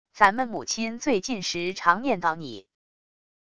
咱们母亲最近时常念叨你wav音频生成系统WAV Audio Player